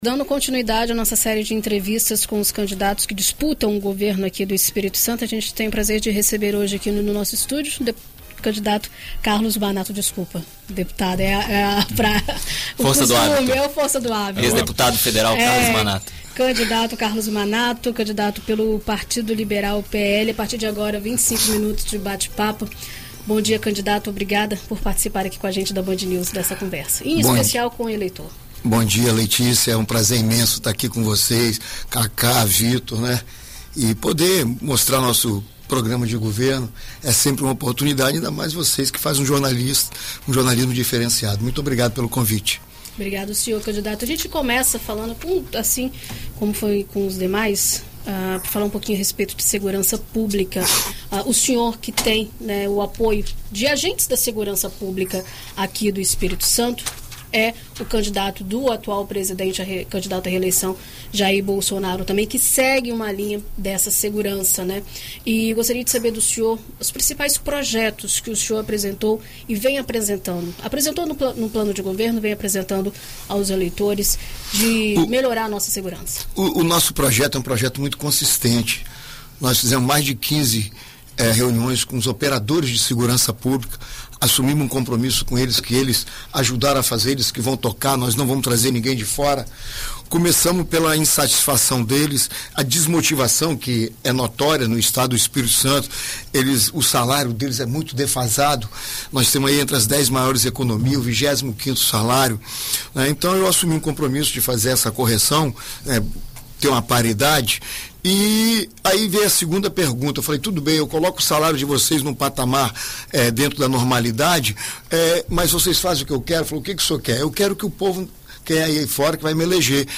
Na série de entrevistas com os candidatos ao Governo do Estado nas eleições deste ano, na BandNews FM Espírito Santo, o candidato pelo Partido Liberal (PL), Carlos Manato, apresenta as propostas para a gestão do governo estadual para o próximo quadriênio.